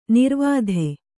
♪ nirvādhe